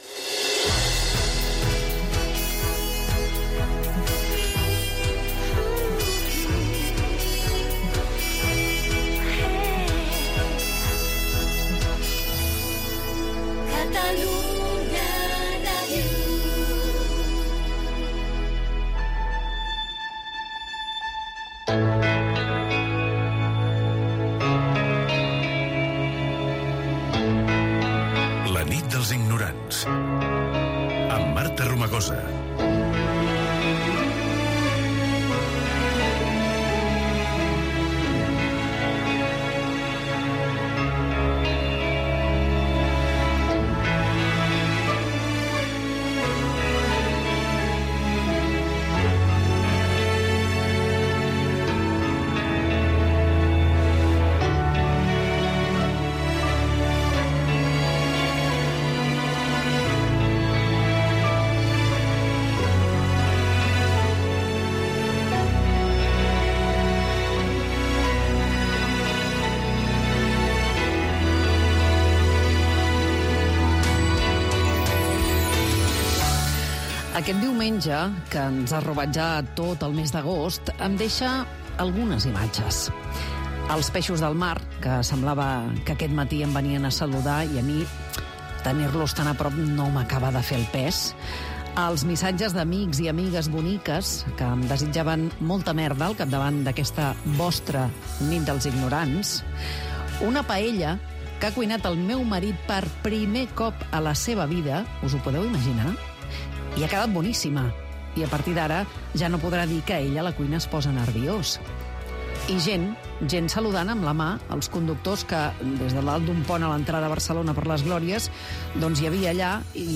ba3ea52aad35b88177f2f28a6145fd07f367d4fa.mp3 Títol Catalunya Ràdio Emissora Catalunya Ràdio Cadena Catalunya Ràdio Titularitat Pública nacional Nom programa La nit dels ignorants Descripció Primera edició de la temporada 2025-2026 amb un nou equip al capdavant del programa. Indicatiu de la ràdio, careta del programa, les imatges del diumenge, equip del programa, telèfon de participació, indicatiu del programa, primeres trucades telefòniques Gènere radiofònic Participació